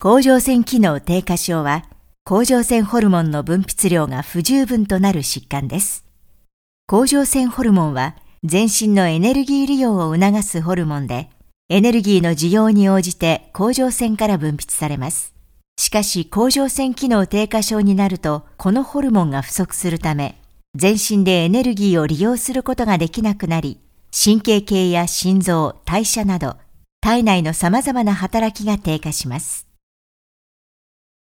JP FS EL 01 eLearning/Training Female Japanese